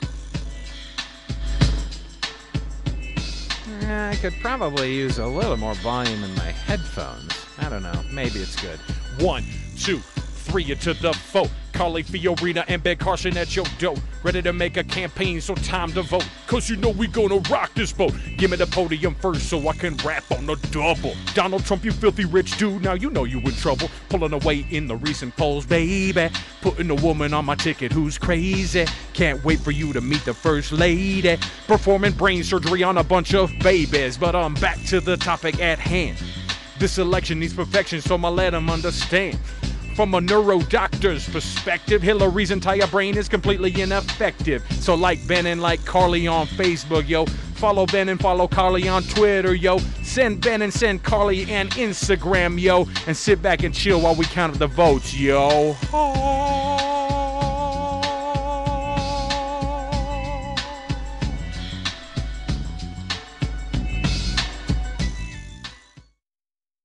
The Nightside Project decided to help out the Republican candidate by providing a new rap song!